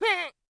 Cat Pain Sound Effect
Download a high-quality cat pain sound effect.
cat-pain-2.mp3